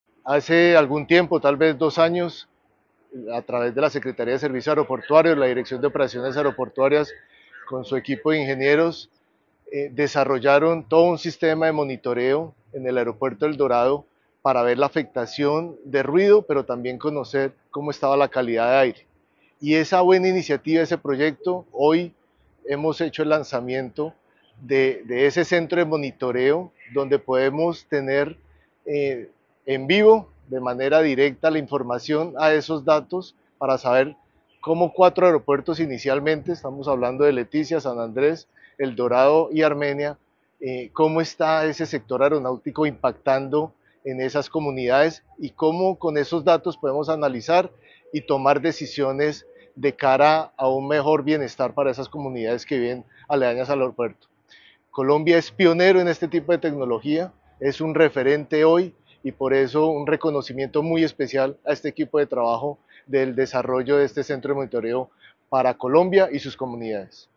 Coronel Andrés Felipe Vargas - Subdirector Aeronáutica Civil